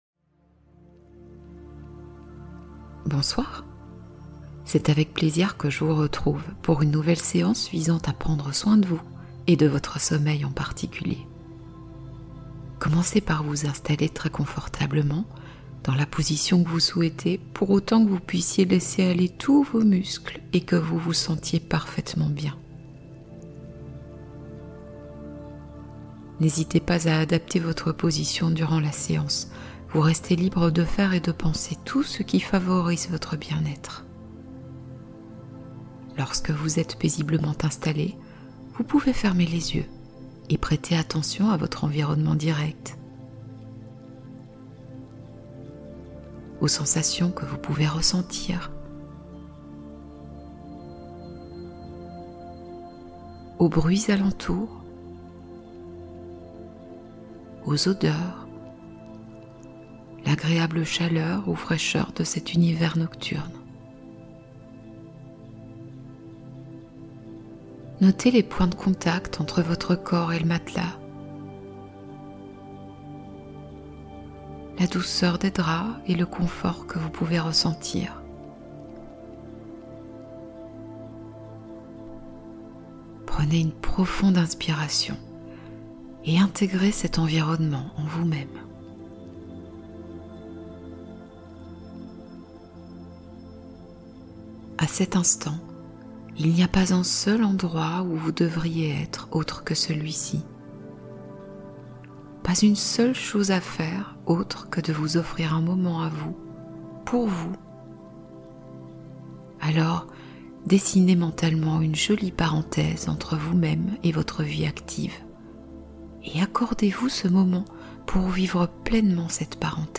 Hypnose du soir : tendresse et douceur avant le repos